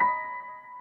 piano71.ogg